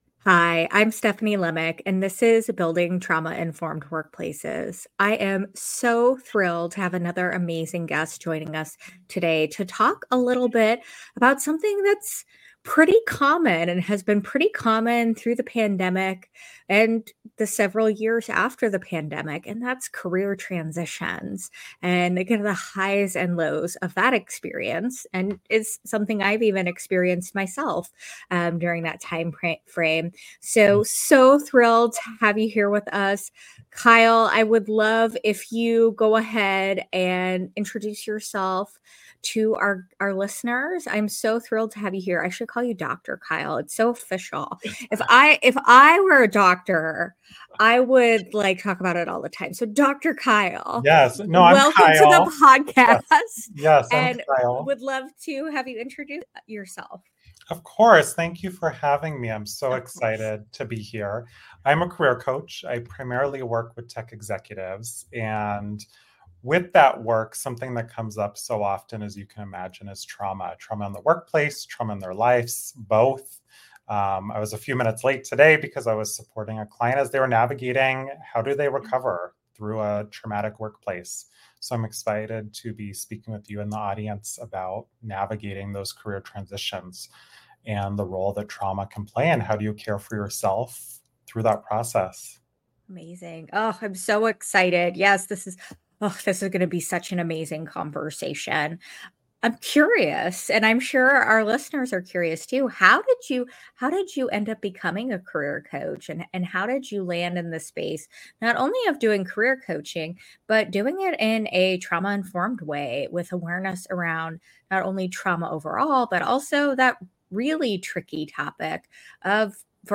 Join us for a conversation on career transitions and how trauma shows up as we navigate these big changes!